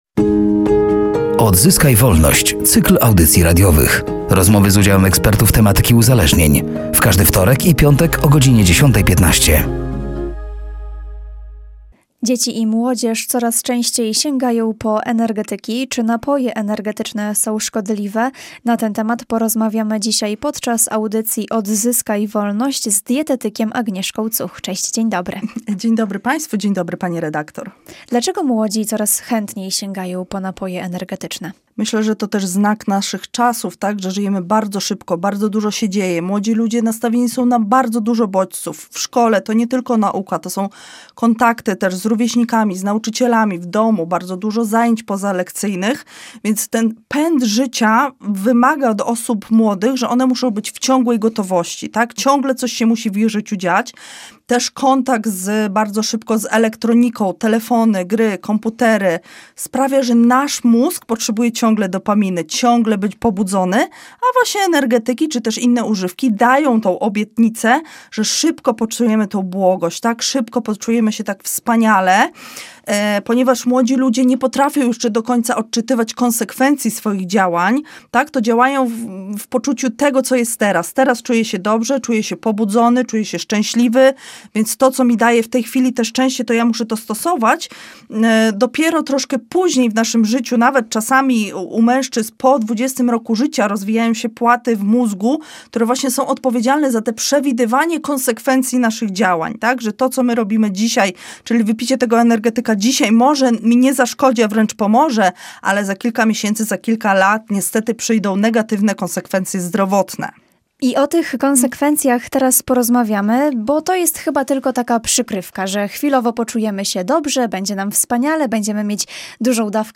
W każdy wtorek i piątek o godzinie 10.15 na antenie Radia Nadzieja, eksperci dzielią się swoją wiedzą i doświadczeniem na temat uzależnień.